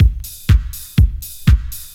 BC Beat 1_122.wav